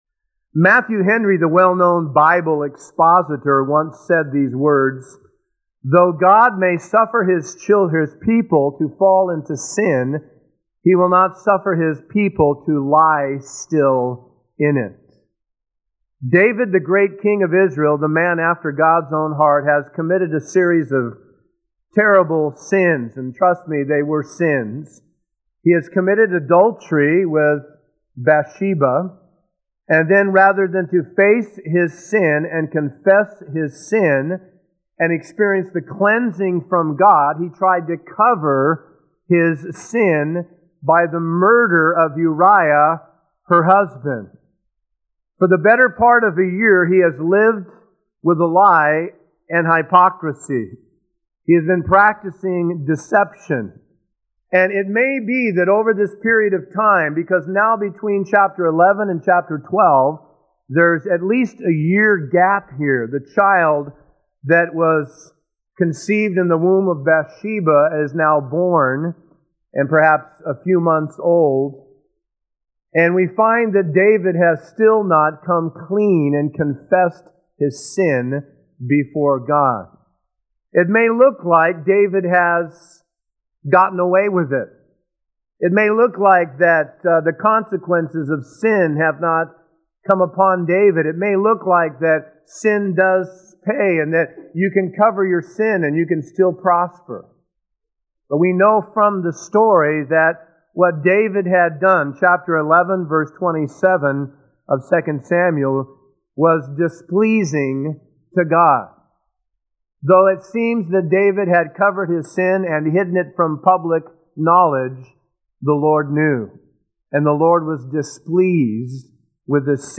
A verse-by-verse sermon through 2 Samuel 12